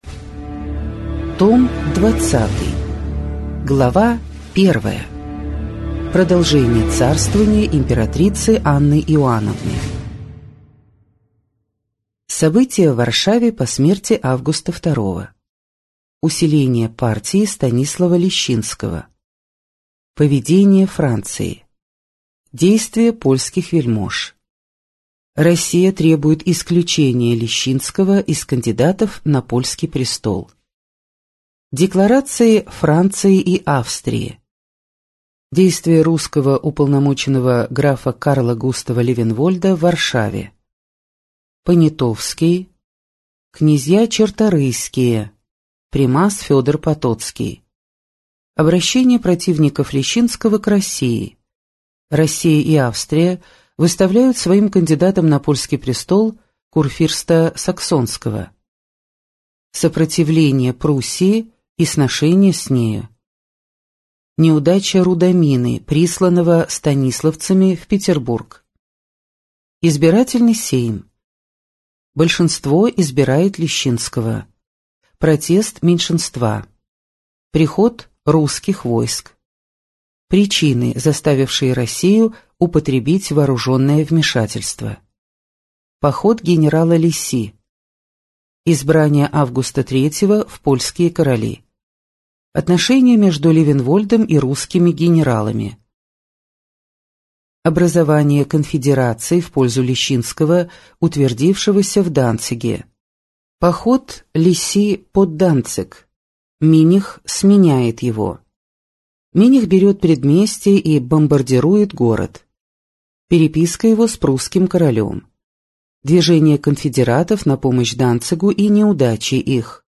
Аудиокнига История России с древнейших времен. Том 20. Царствование императрицы Анны Иоанновны | Библиотека аудиокниг